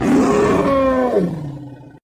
death3.wav